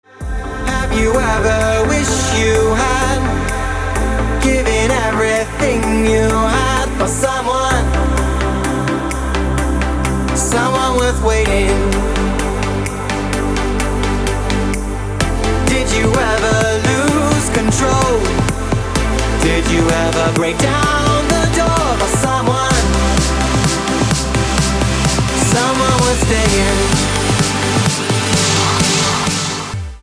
• Качество: 320, Stereo
мужской вокал
dance
Electronic
EDM
электронная музыка
progressive house